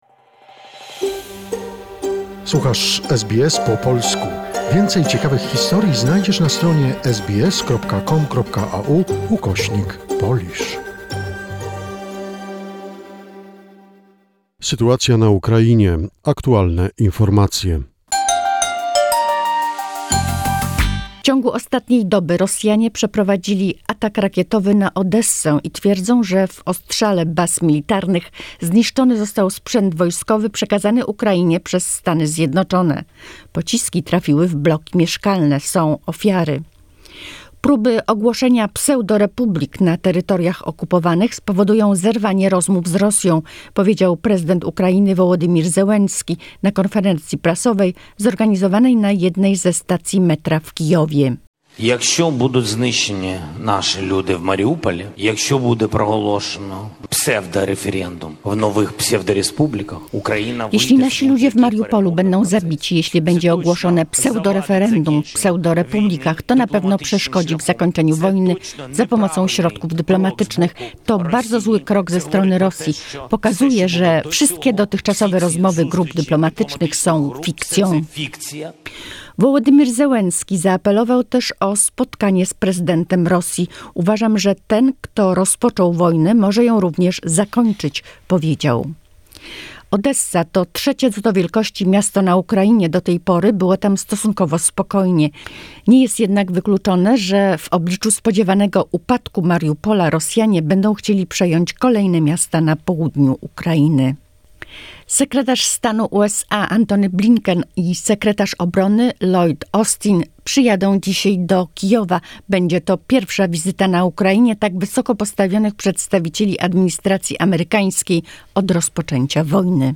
The most recent information about the situation in Ukraine - a short report by SBS Polish.